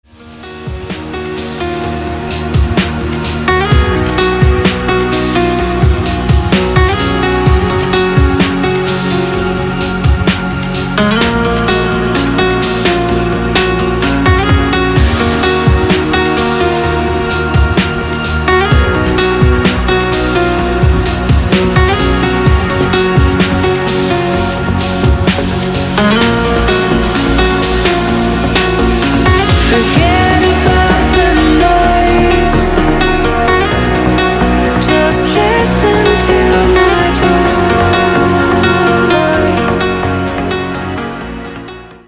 • Качество: 320, Stereo
Electronic
спокойные
расслабляющие
Стиль: trance